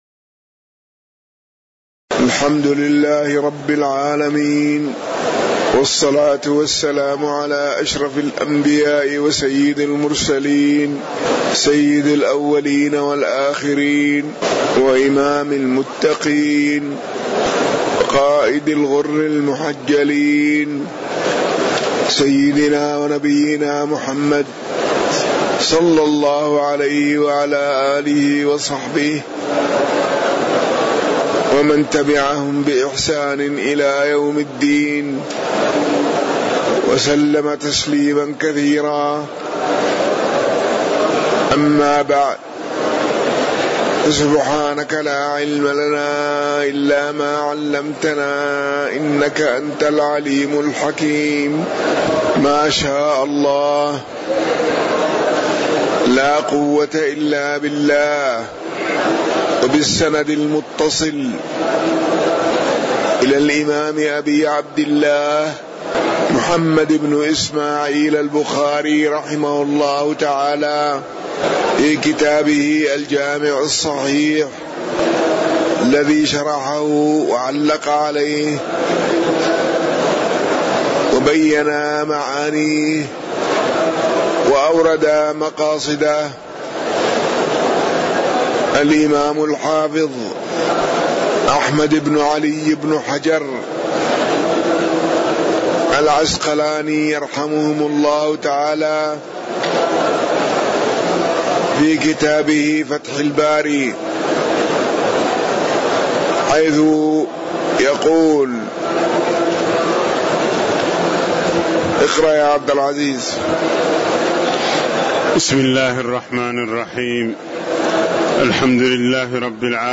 تاريخ النشر ٢٦ جمادى الأولى ١٤٣٩ هـ المكان: المسجد النبوي الشيخ